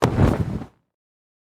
Gymnastics Mat Land
Gymnastics Mat Land is a free sfx sound effect available for download in MP3 format.
yt_GKSN6OuroIc_gymnastics_mat_land.mp3